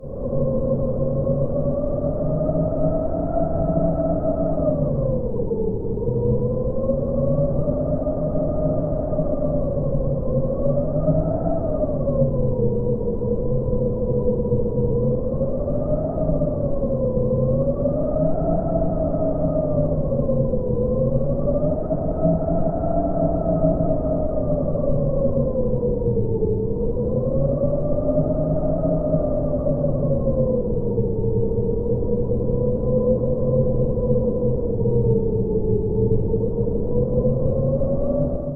Wind Ambience.mp3